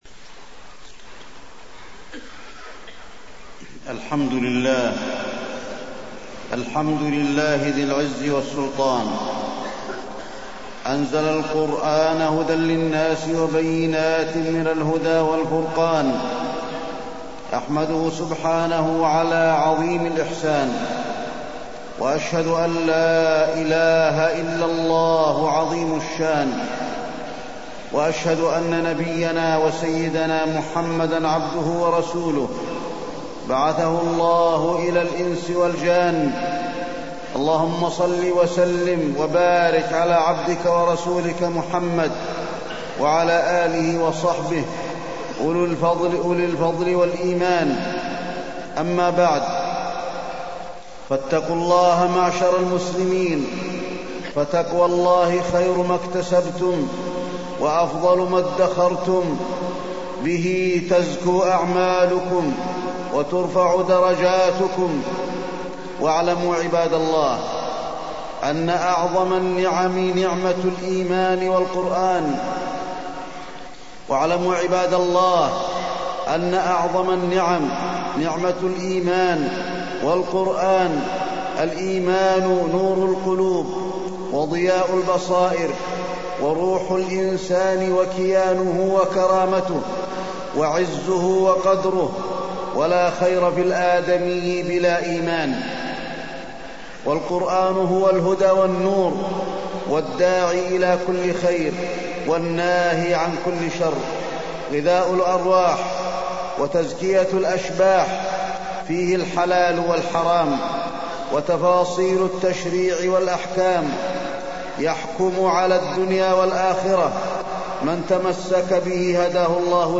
تاريخ النشر ١٠ رمضان ١٤٢٣ هـ المكان: المسجد النبوي الشيخ: فضيلة الشيخ د. علي بن عبدالرحمن الحذيفي فضيلة الشيخ د. علي بن عبدالرحمن الحذيفي معجزة القرآن الكريم